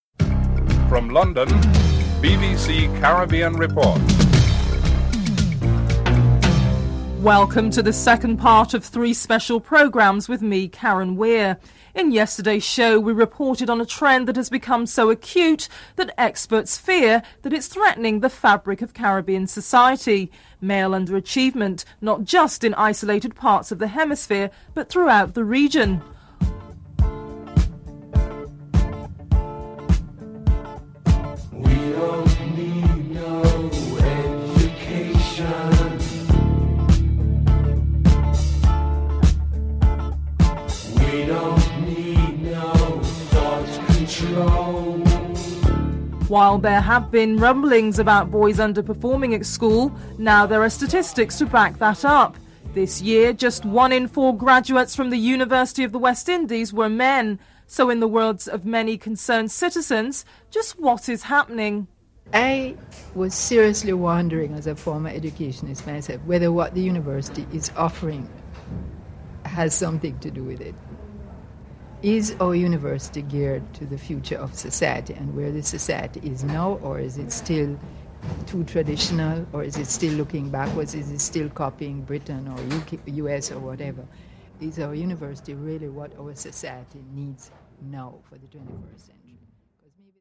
1. Headlines: (00:00-00:27)